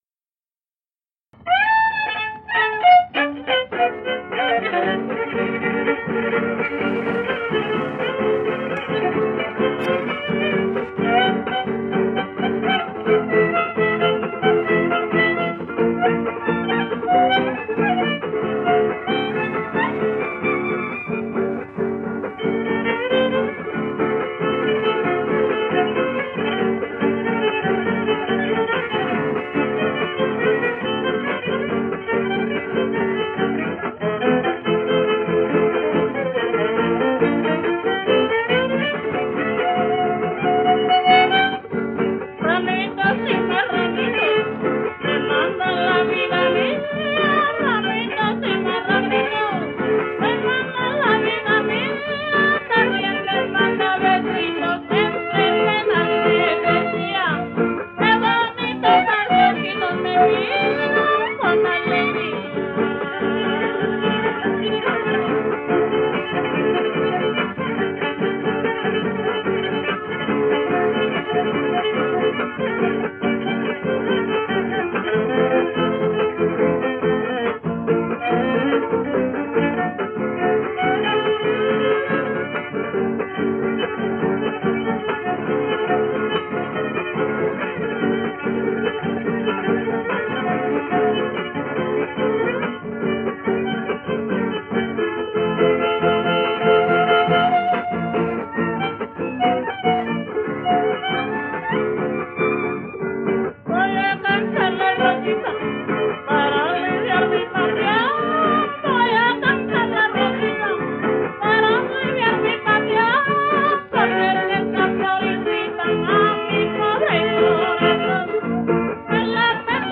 Keywords: huapangos poblanos
violín y voz
voz y jarana
voz, guitarra sexta y guitarra quinta
trovador.